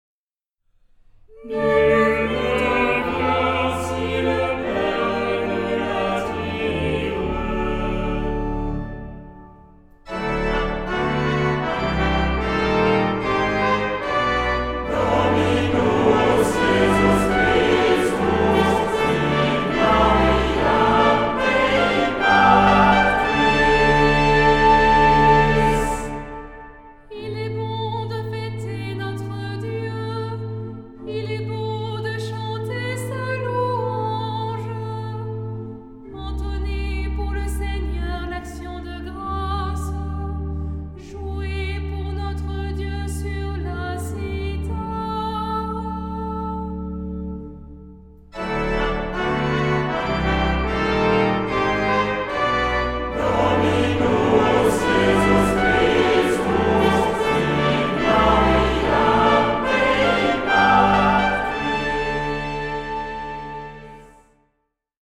Genre-Style-Forme : Tropaire ; Psalmodie
Caractère de la pièce : recueilli
Type de choeur : SATB  (4 voix mixtes )
Instruments : Orgue (1) ; Instrument mélodique (1)
Tonalité : ré mineur ; ré majeur
interprété par Alto solo
Trompette